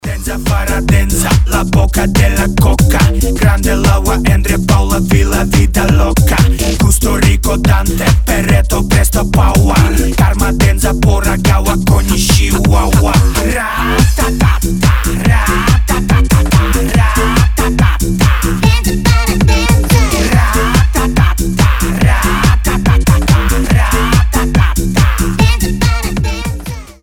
энергичные
быстрые
бодрые
Rave
рейв